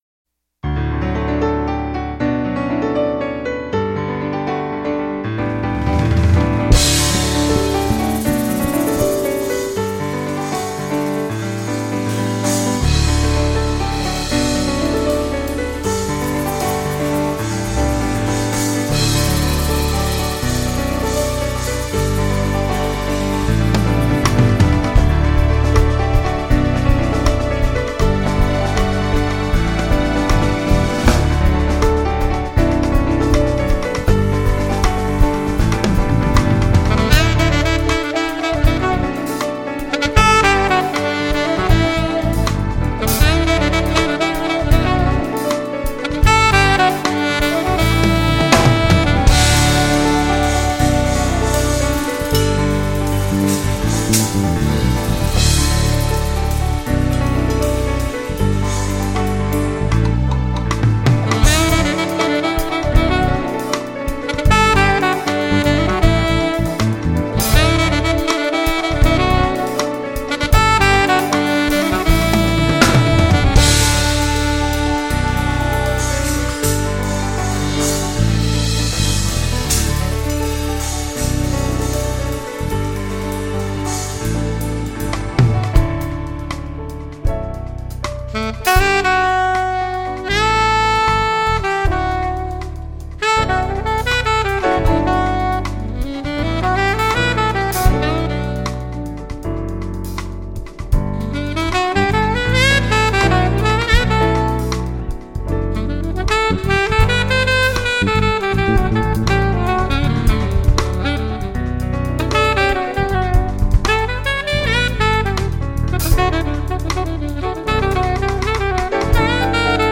piano and keyboard
saxophone and keyboard
bass
drums